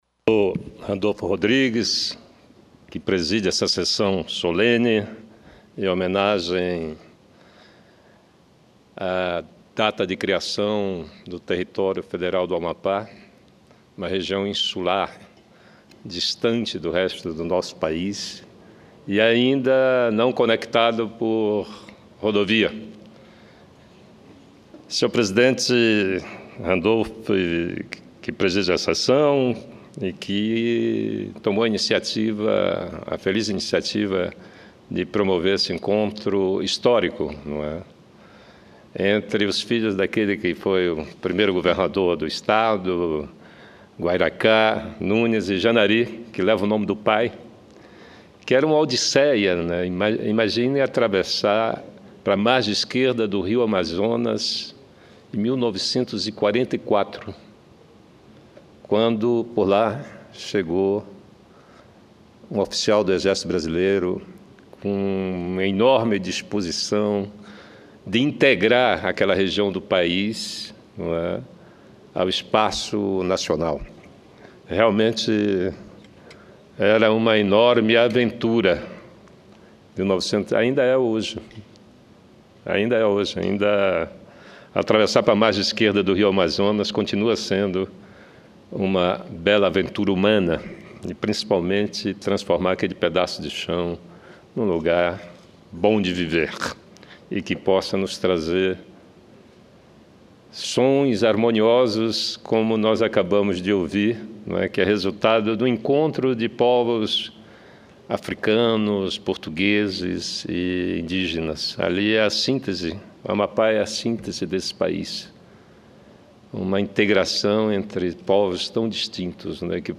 Pronunciamento do senador João Capiberibe 35:45 Pronunciamento do procurador geral da justiça do Amapá, Roberto Álvares 13:34 Pronunciamento do prefeito de Serra do Navio, José Maria 17:11 Pronunciamento do senador Randolfe Rodrigues 21:57